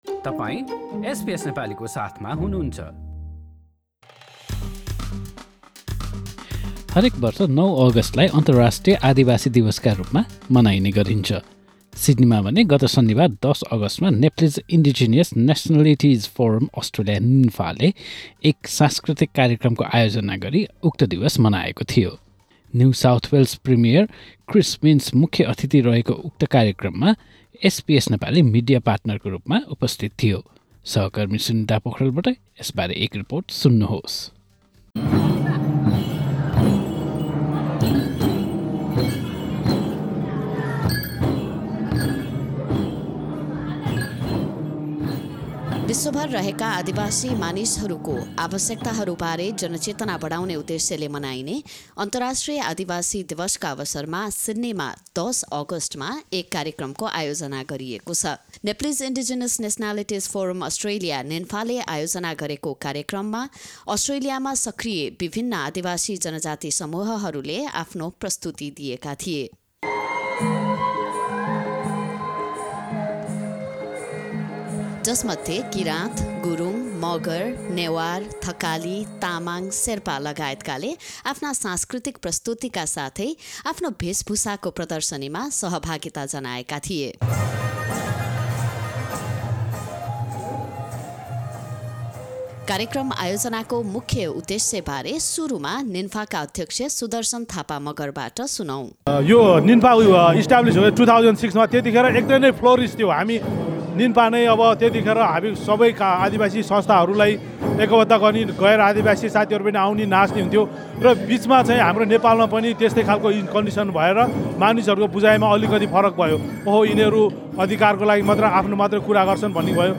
यसबारे एक रिपोर्ट।